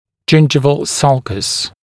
[‘ʤɪnʤɪvəl ˈsʌlkəs] [ʤɪn’ʤaɪvəl][‘джиндживэл ˈсалкэс] [джин’джайвэл]десневая борозда